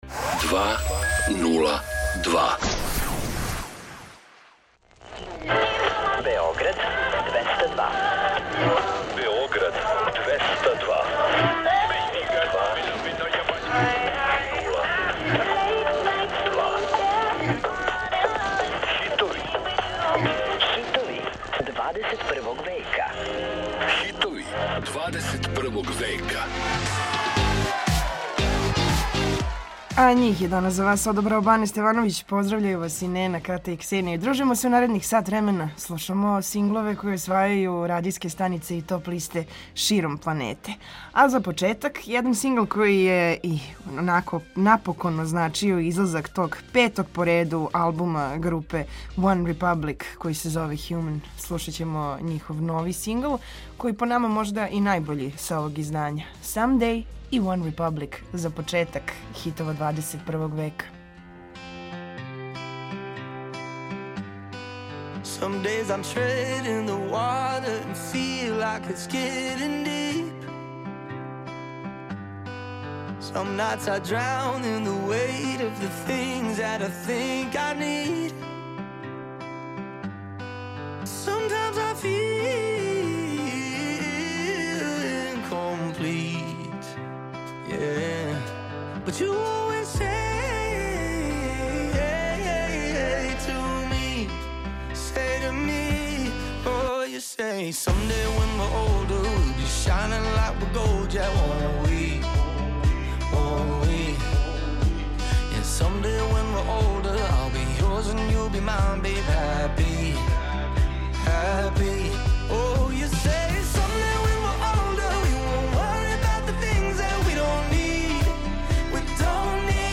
Slušamo hitove novog milenijuma, koje osvajaju top liste i radijske stanice širom planete.